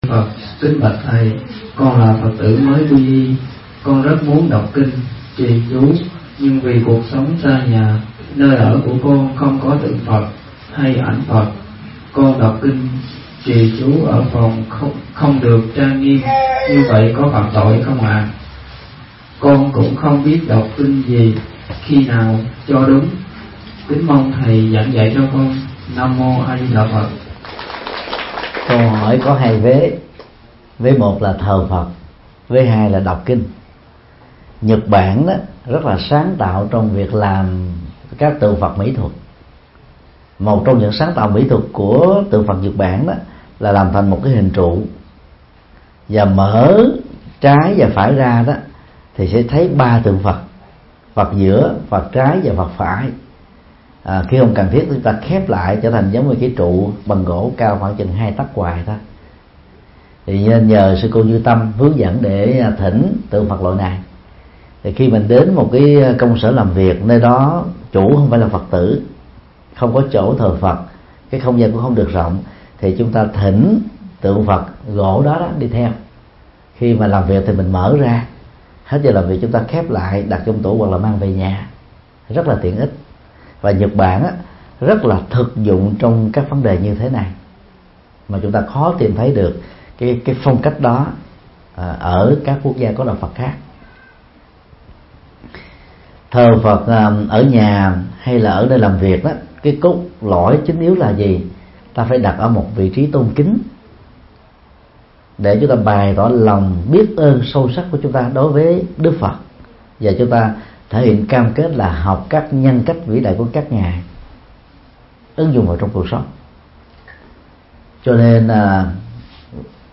Vấn đáp: Hướng dẫn tụng kinh trì chú trong phòng trọ – thầy Thích Nhật Từ